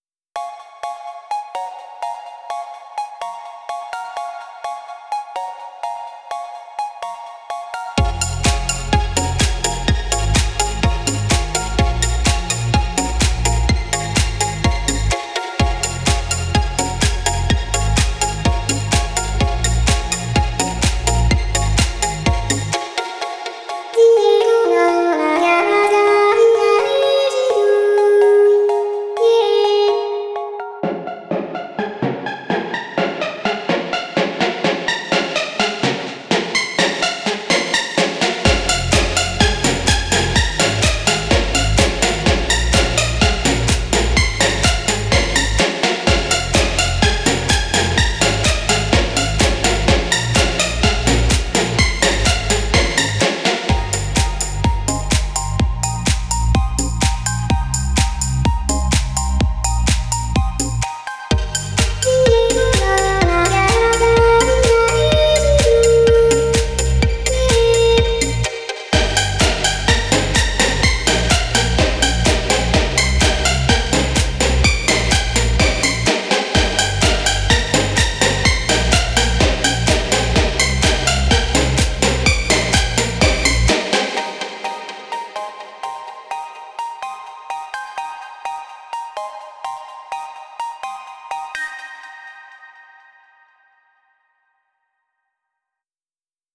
ELECTRO G-N (38)